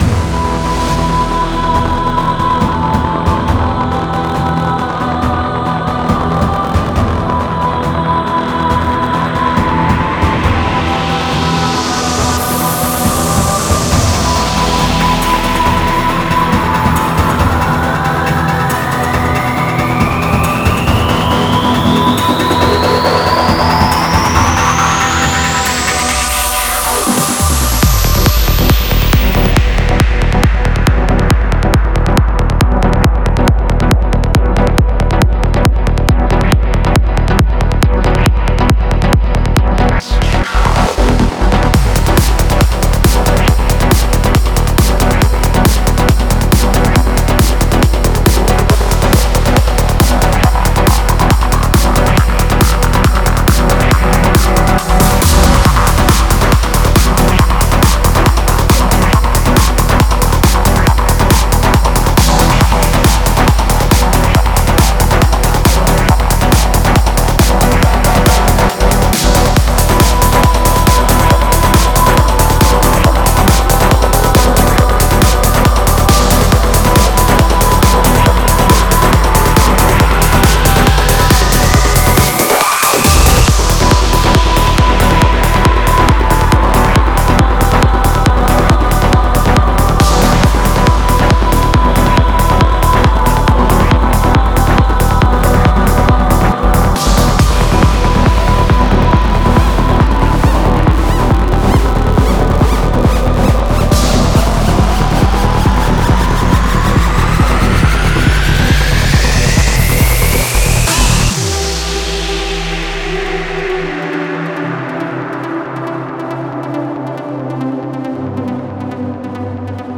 Стиль: Tech Trance